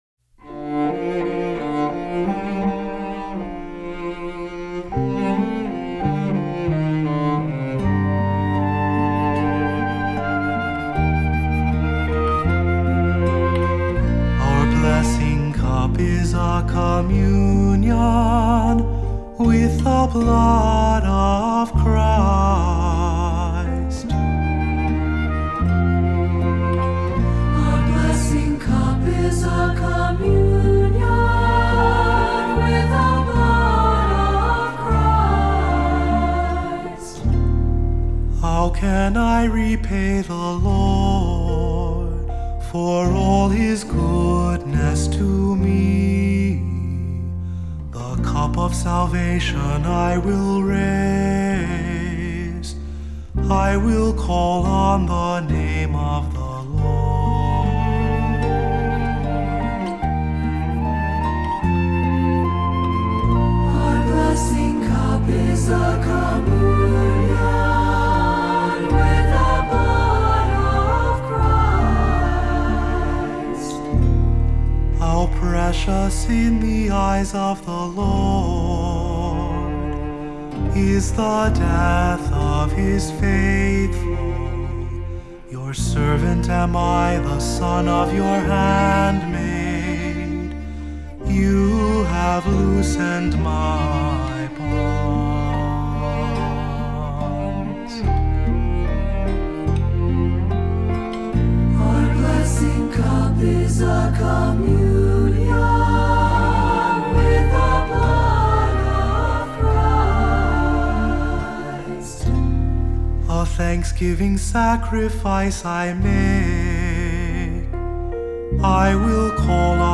Voicing: Three-part choir; Cantor; Assembly